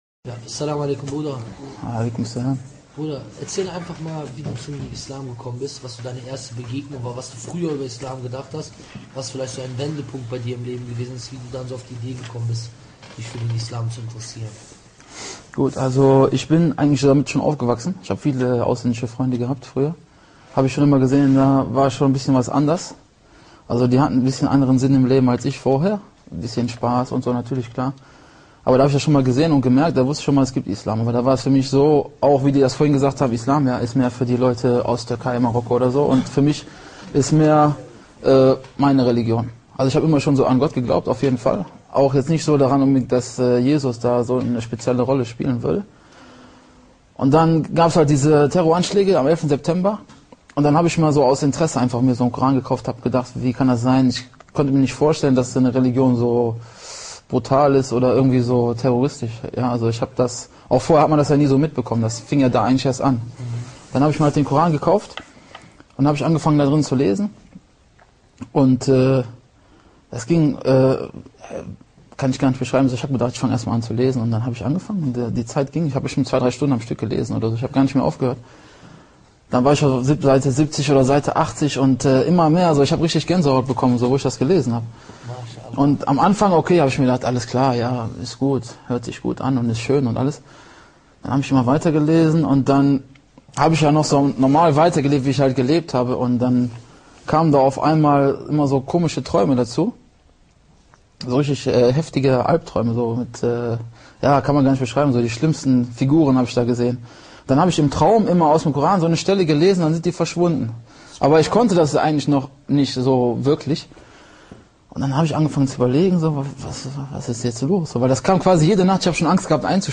In this video a German who converted to Islam tells how did he came to Islam.